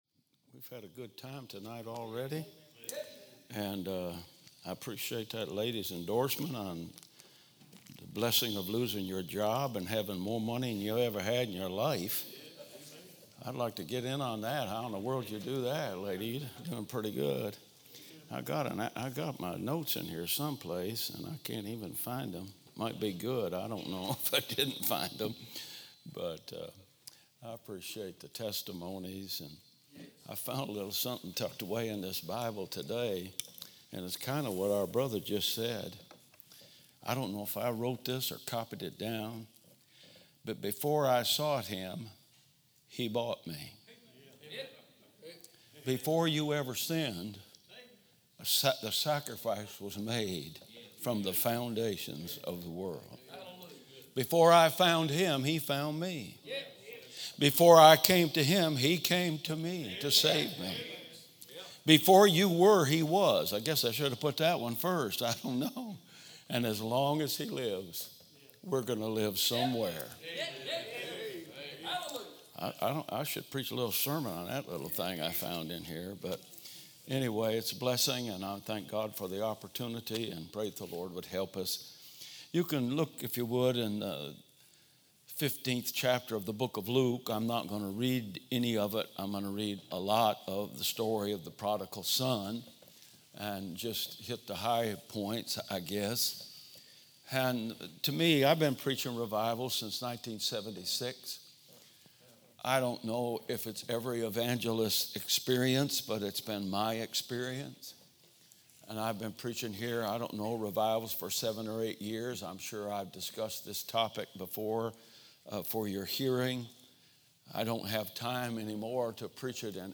From Series: "2024 Spring Revival "